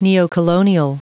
Prononciation du mot neocolonial en anglais (fichier audio)
Prononciation du mot : neocolonial